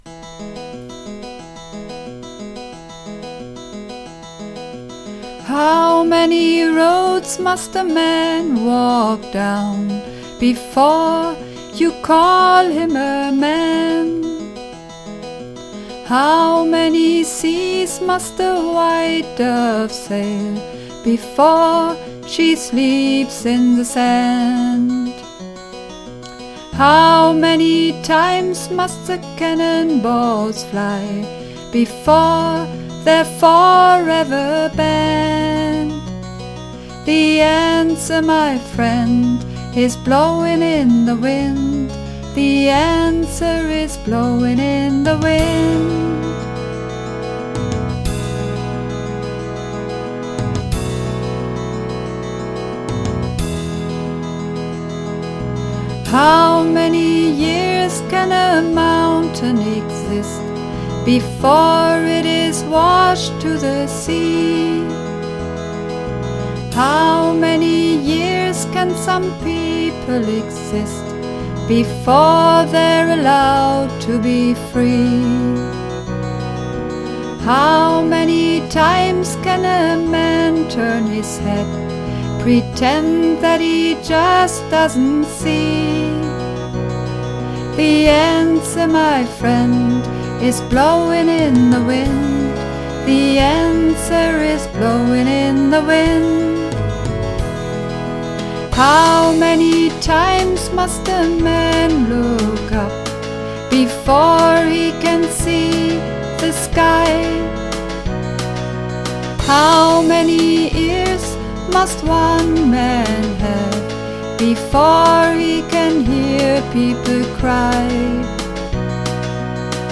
Übungsaufnahmen
Blowin' In The Wind (Alt)
Blowin_In_The_Wind__1_Alt.mp3